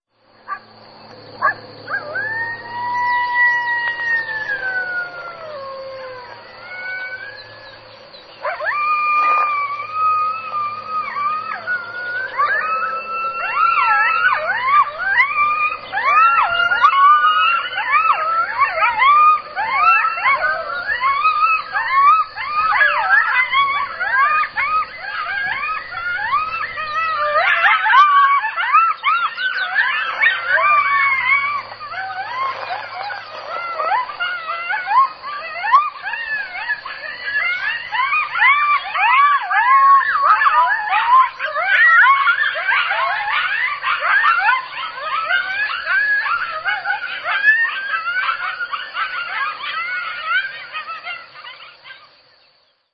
Звуки записаны в естественной среде обитания и идеально подходят для ознакомления с фауной, создания атмосферы в проектах или использования в качестве звуковых эффектов.
Вой койотов под светом полной луны